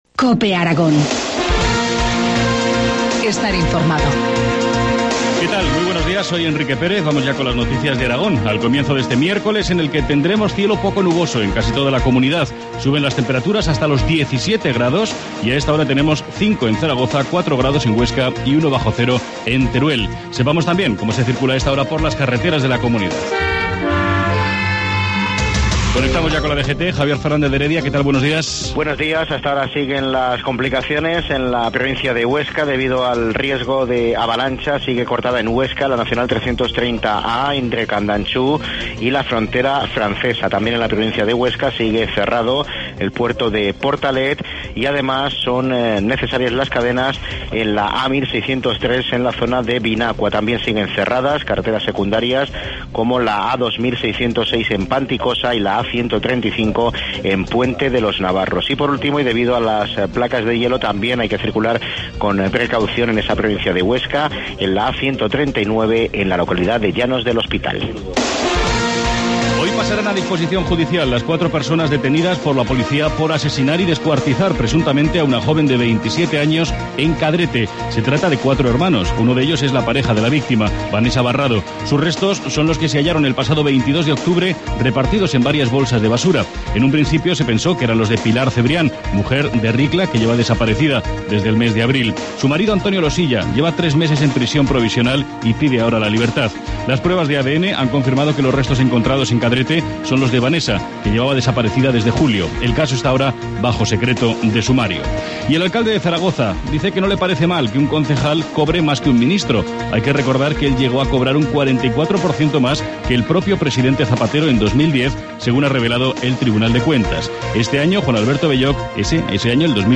Informativo matinal, miércoles 30 de enero, 7.25 horas